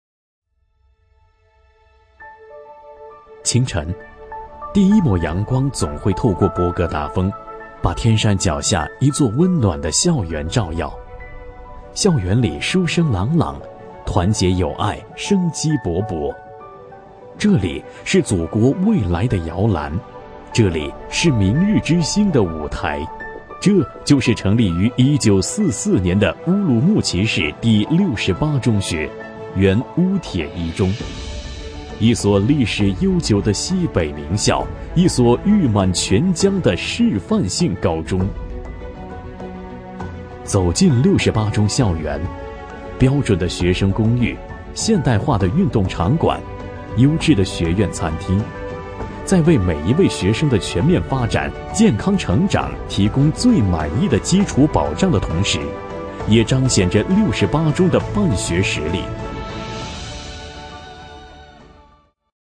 专题男配
【男19号专题片】学校类专题